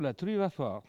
Saint-Hilaire-de-Riez
Catégorie Locution